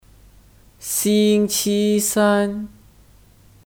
星期 Xīngqī (Kata benda): Minggu (Weeks)Alternatif 周 Zhōu dan 礼拜 Lǐbài